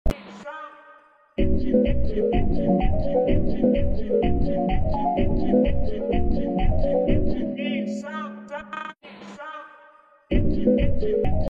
dreamcore Soundboard Sound Buttons - MP3 Download
Meme Sound Effects